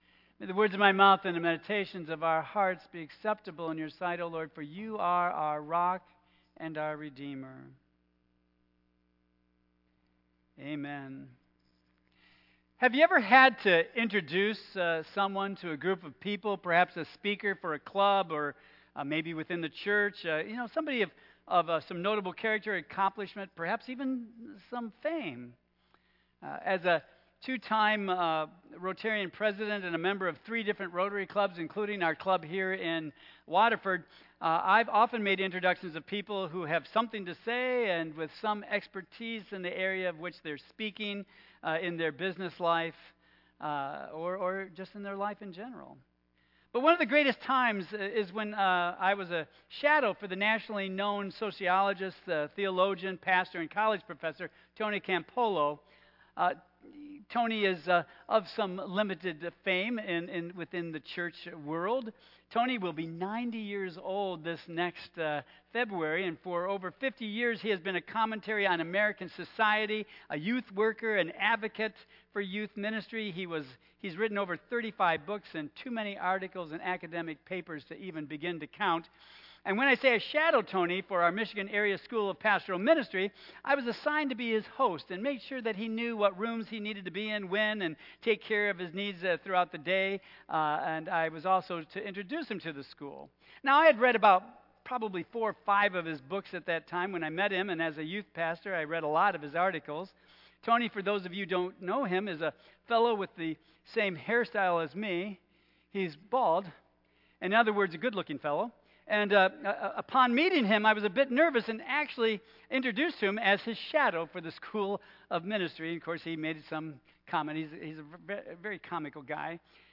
Tagged with Michigan , Sermon , Waterford Central United Methodist Church , Worship